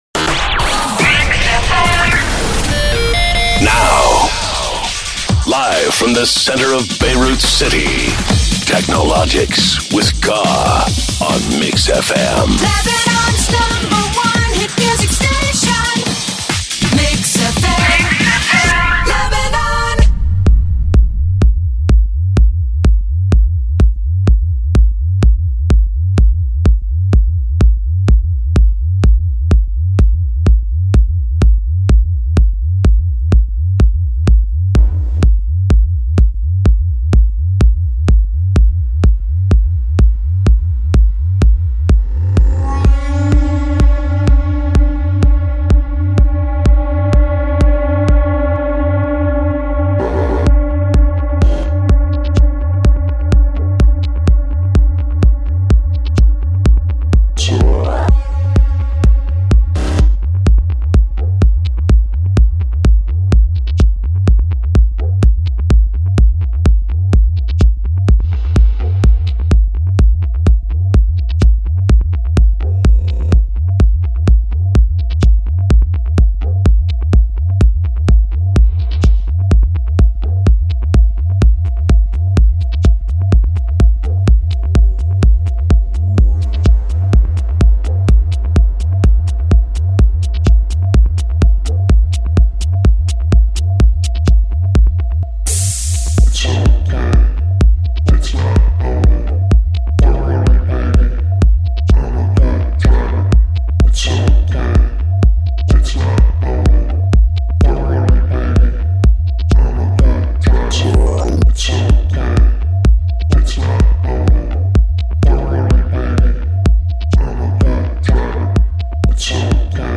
Live from the center of Beirut
minimal, progressive and techno